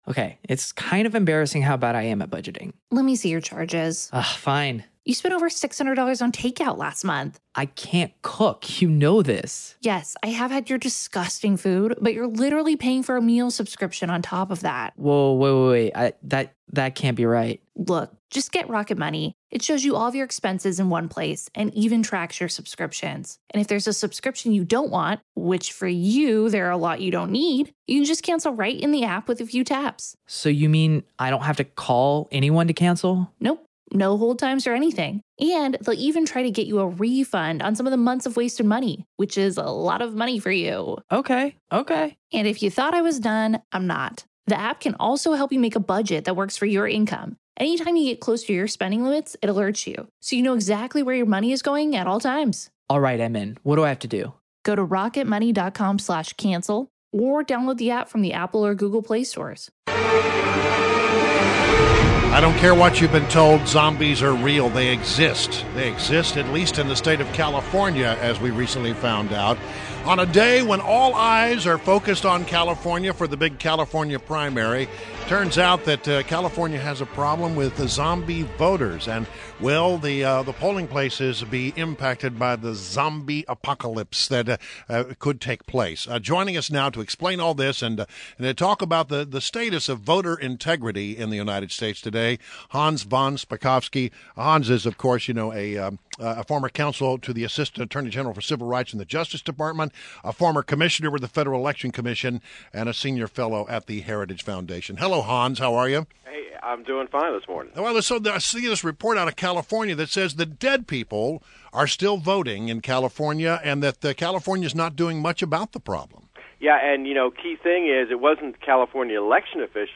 INTERVIEW - HANS VON SPAKOVSKY (HAHNZ VON SPAH-KOV-SKEE)